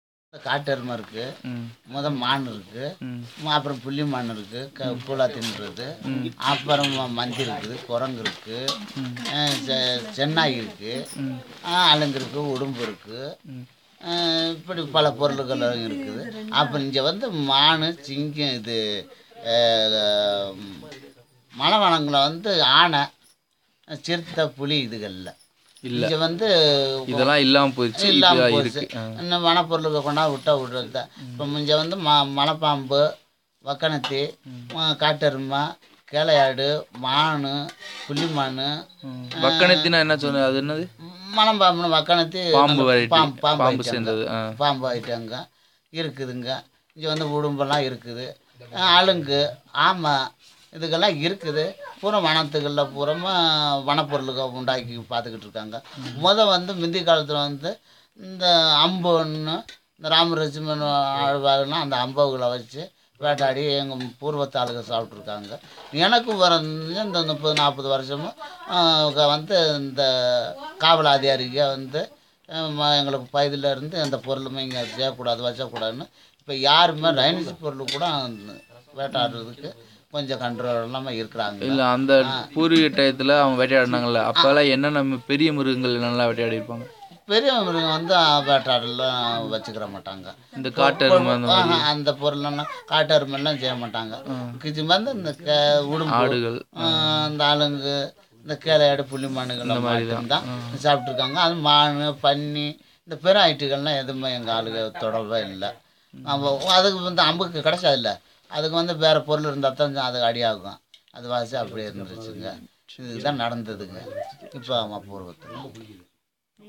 NotesThis is a description by the consultant about the processes of hunting and gathering of honey.